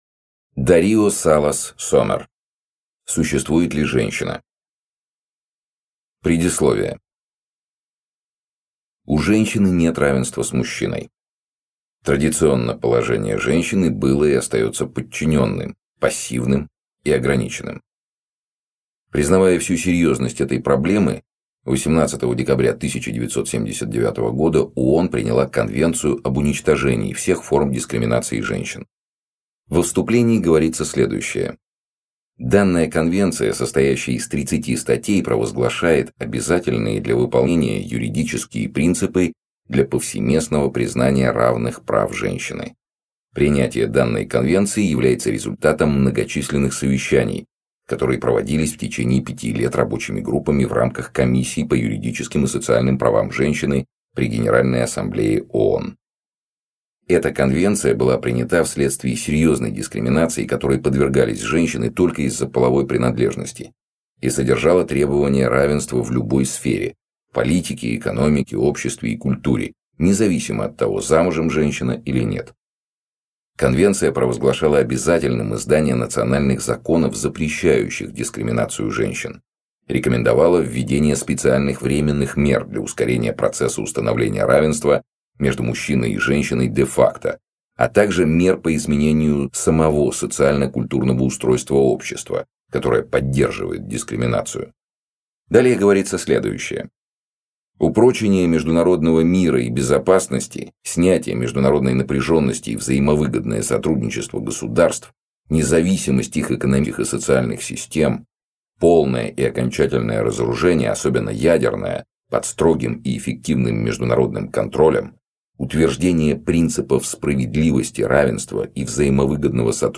Аудиокнига Существует ли женщина?